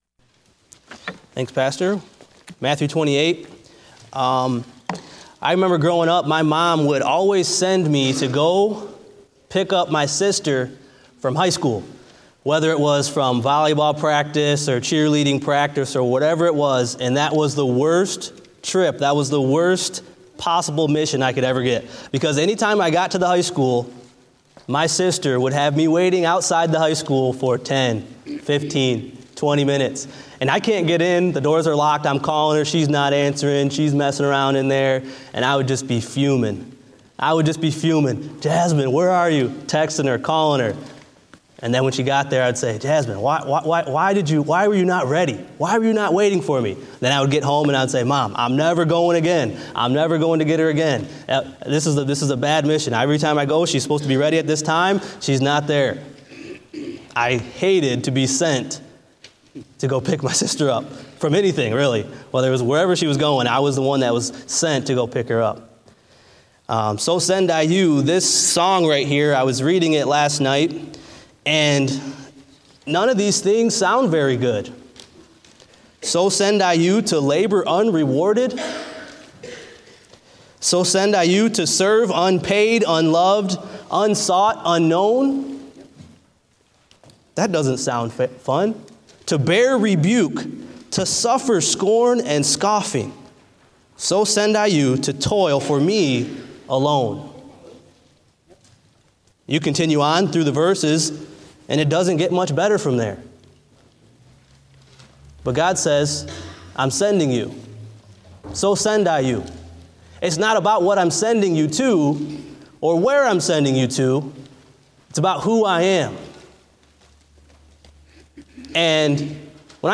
Date: February 19, 2017 (Morning Service)